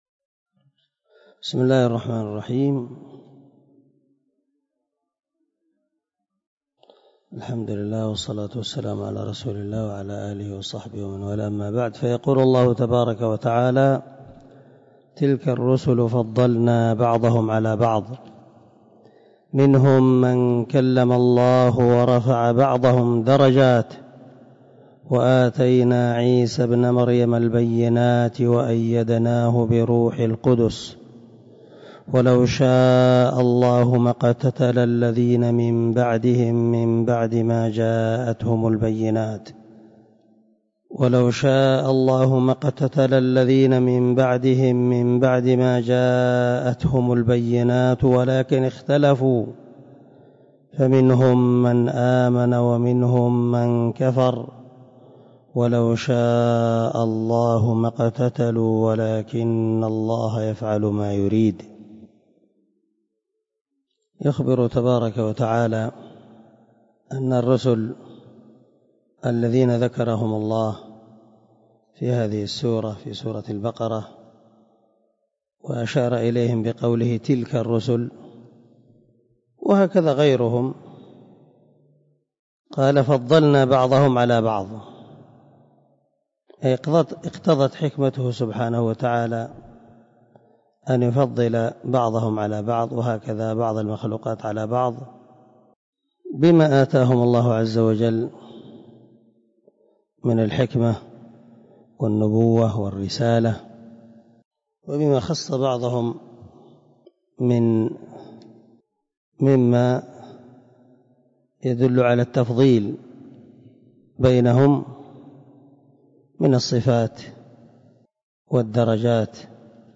131الدرس 121 تفسير آية ( 253 ) من سورة البقرة من تفسير القران الكريم مع قراءة لتفسير السعدي